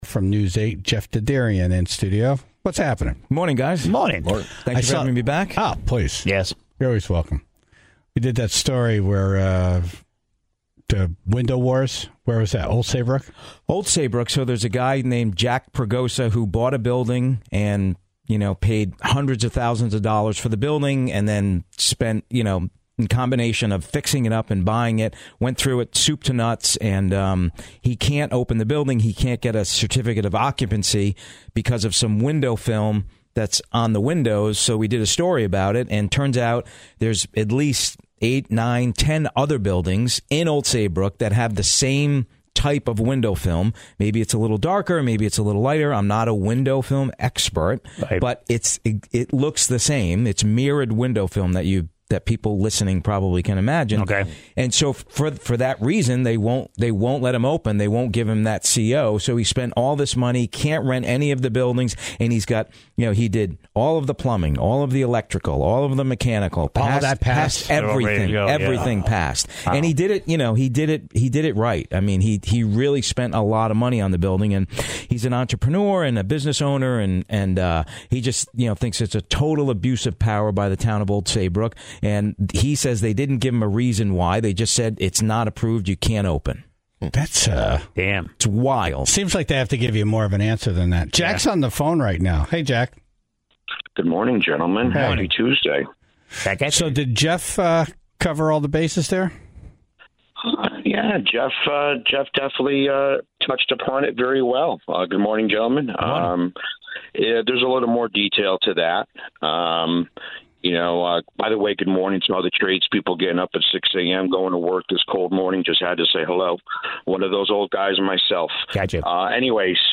in studio this morning